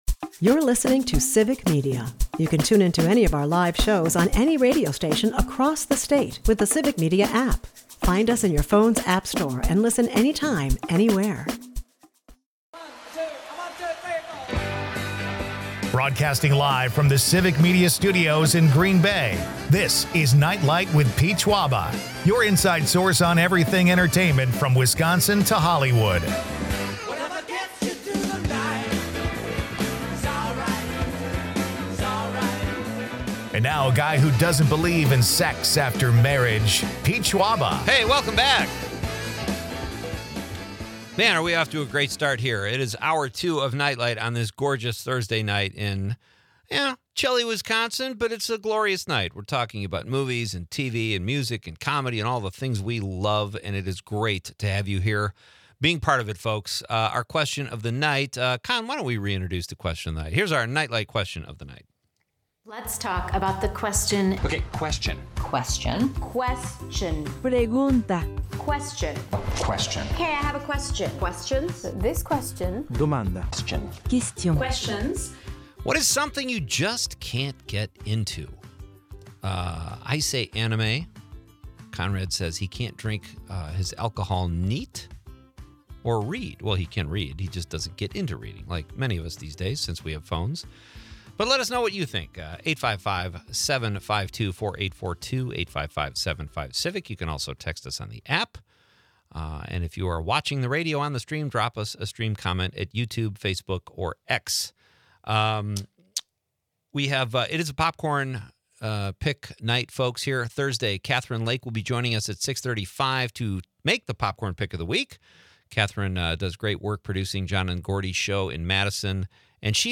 Listeners chime in on things they just can’t get into, from reality TV to country music.